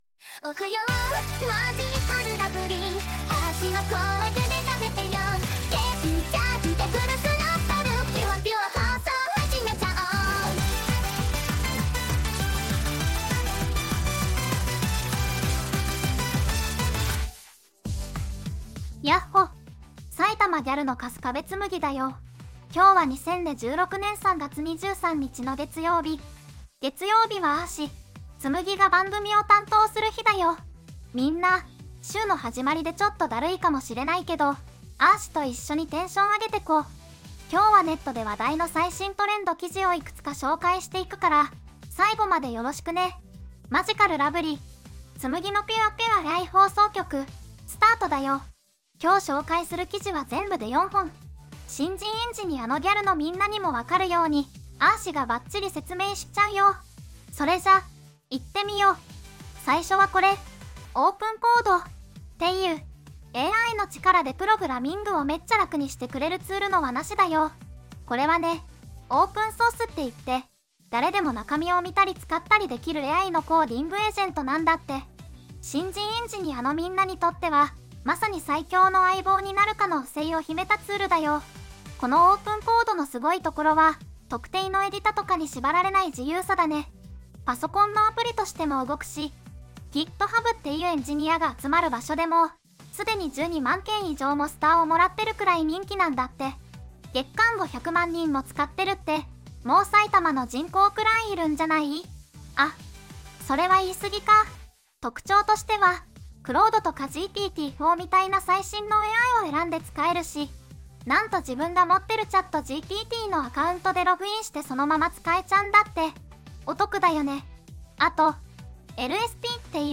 VOICEVOX:春日部つむぎ